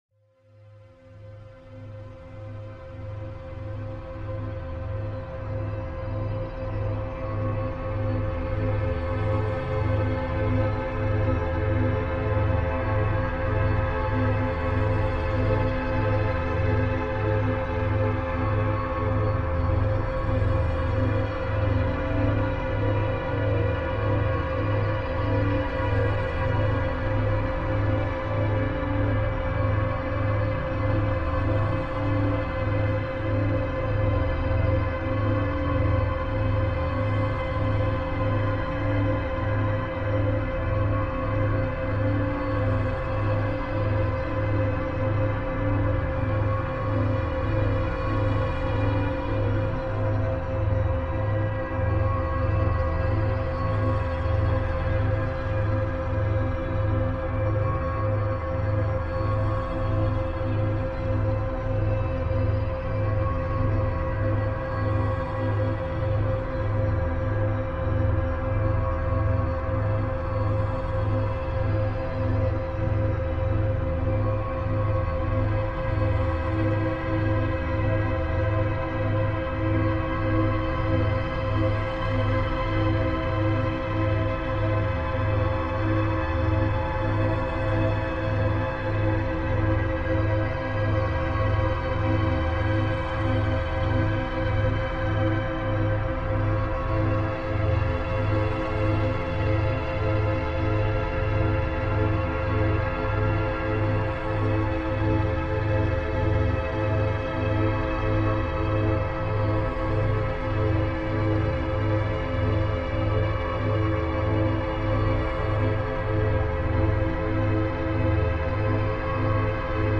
Study Sounds French